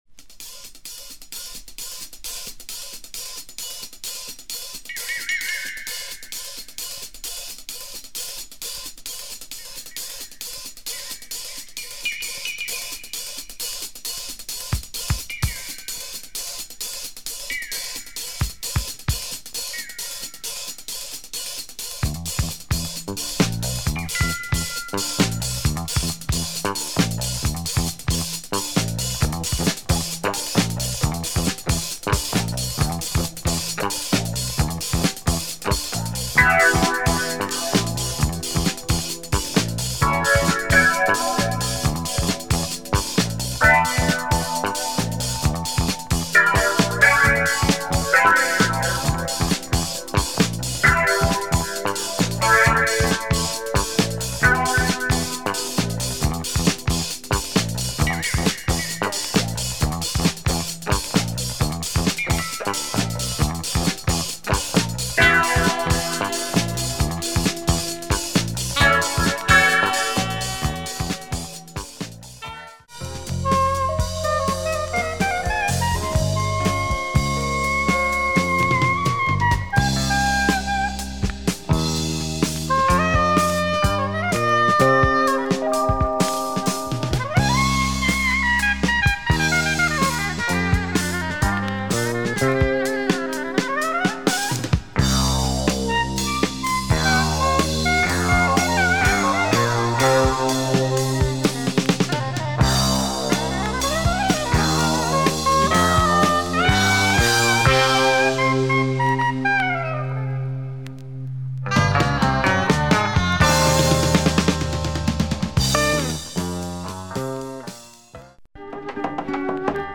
this is balkan funk
and jazz
they know how to groove !